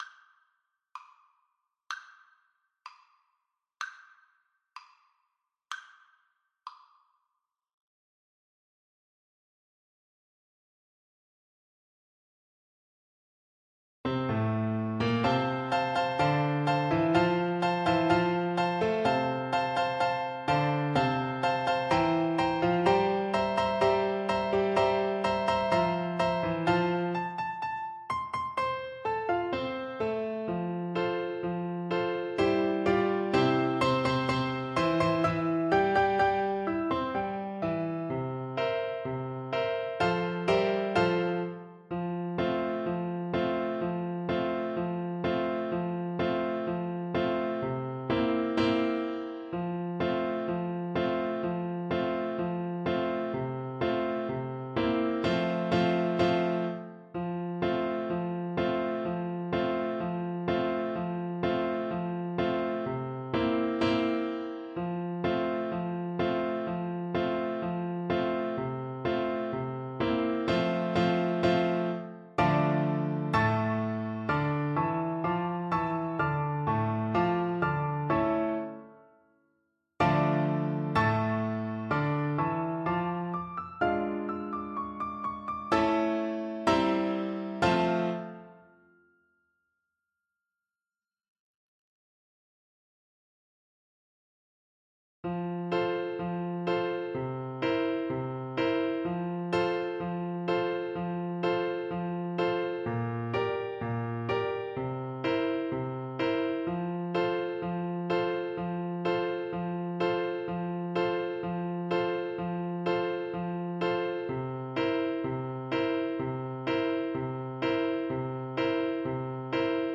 2/4 (View more 2/4 Music)
Arrangement for Flute and Piano
Classical (View more Classical Flute Music)